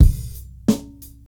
LA Straight 89bpm.wav